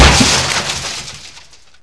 sound / rally / car / damage50_1.ogg
damage50_1.ogg